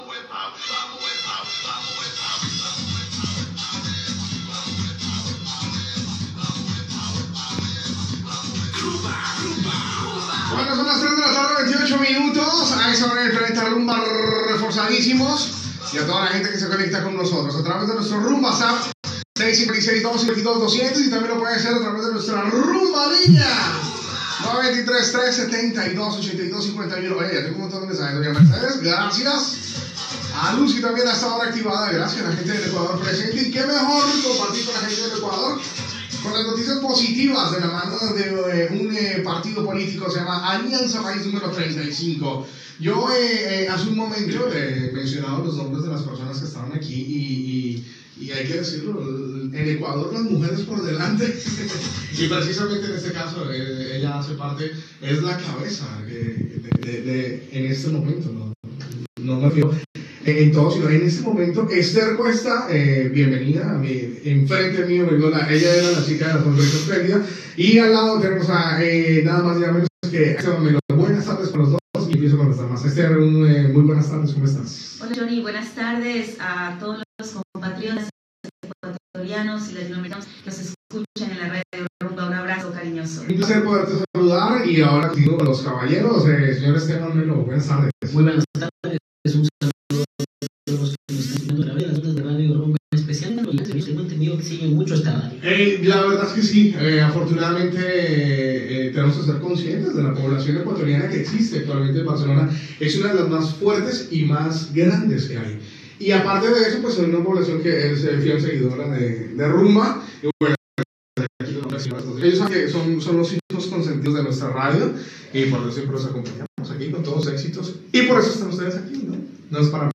Hora, salutació, telèfon de participació, presentació de la polítia equatoriana Esther Cuesta del partit Alianza País que està a Barcelona
FM